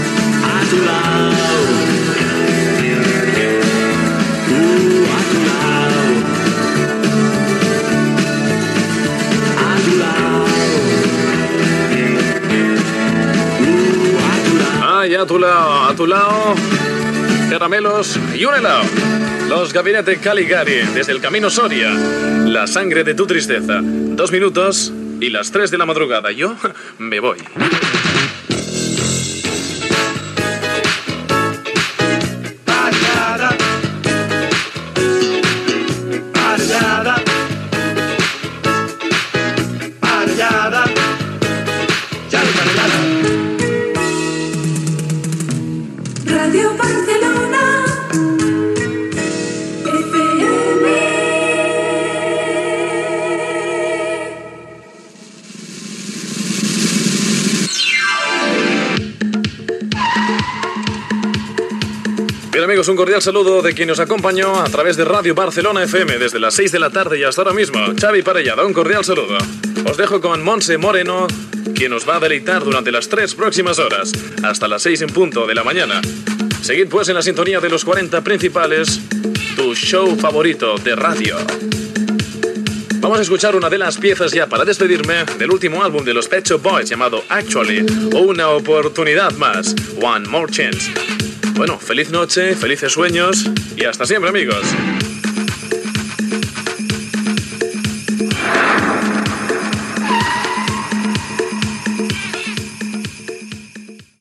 Tema musical, comentari sobre el tema que ha sonat, hora, indicatiu del locutor i de la ràdio, comiat i tema musical.
Musical